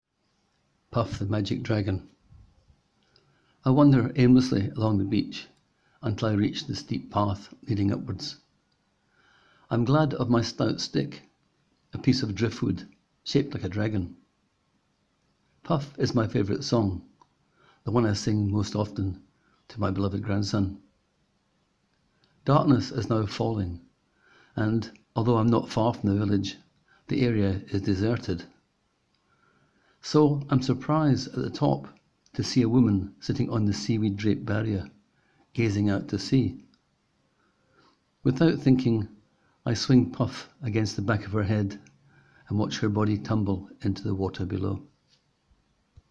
Click here to hear the writer read his words: